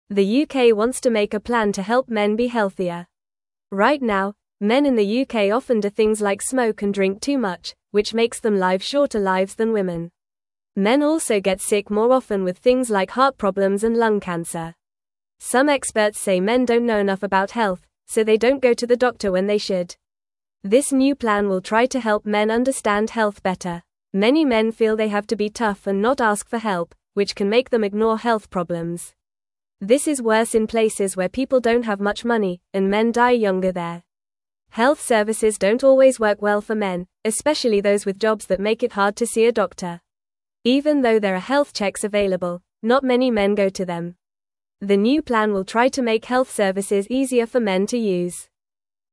Fast
English-Newsroom-Beginner-FAST-Reading-Helping-Men-Be-Healthier-in-the-UK.mp3